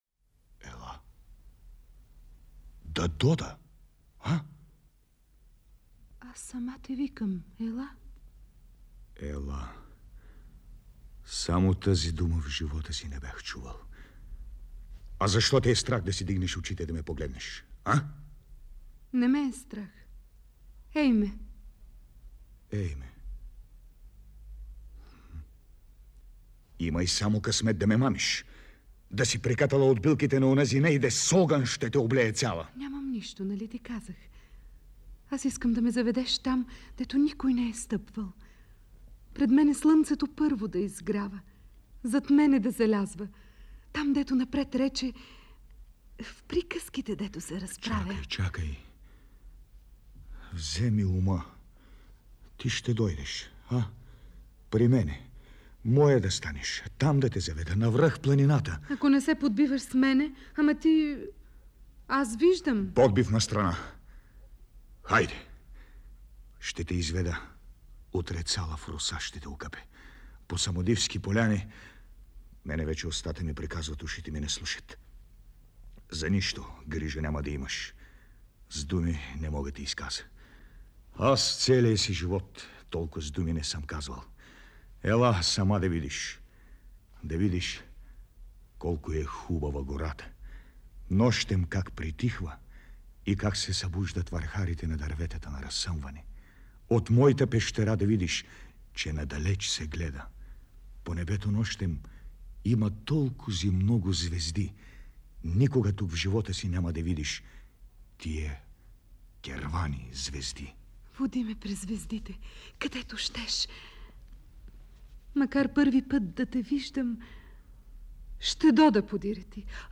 „Змейова сватба” – постановка на Радиотеатъра
Запис 1966 година, Златен фонд на БНР: